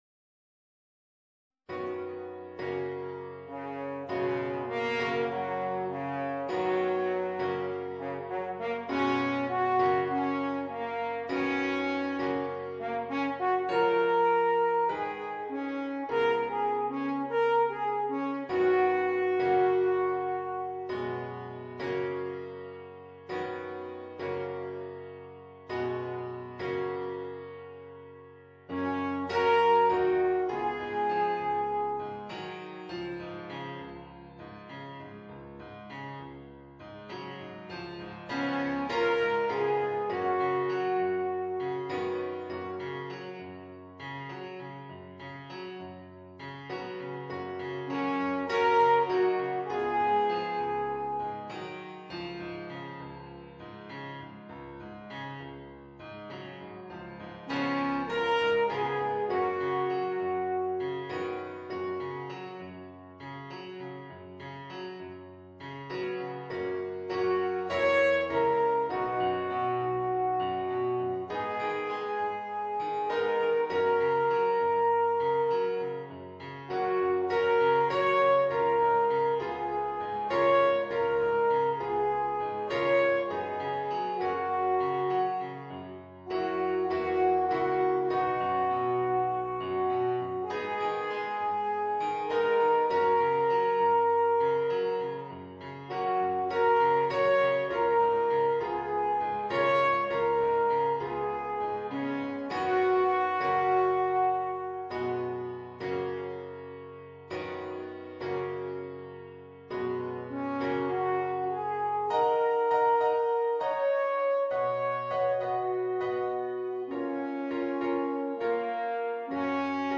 Voicing: Alphorn w/ Audio